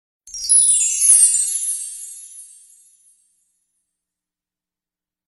SFX魔法粒子小魔仙音效下载
SFX音效